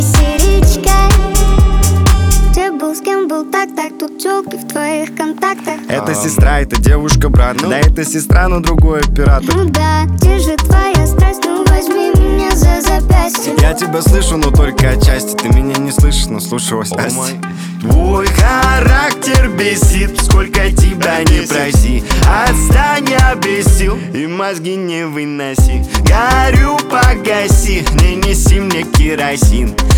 Жанр: Русские
# Поп